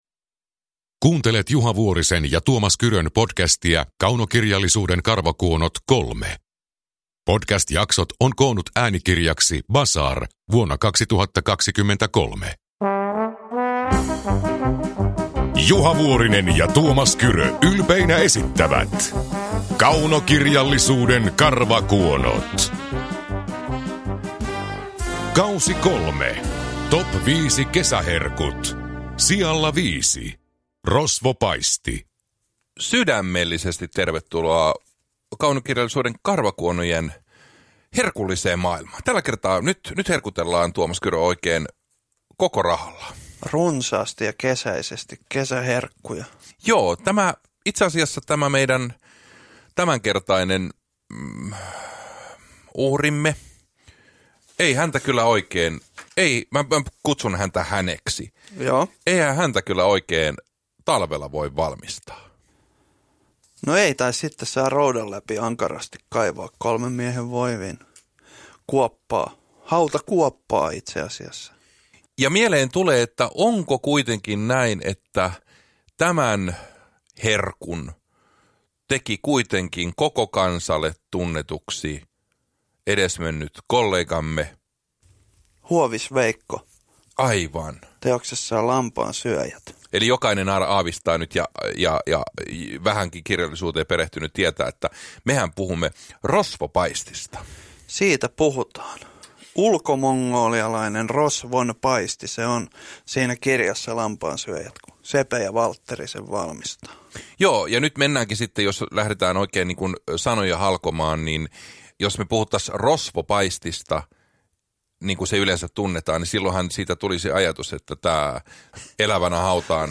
Kaunokirjallisuuden karvakuonot K3 – Ljudbok
Uppläsare: Tuomas Kyrö, Juha Vuorinen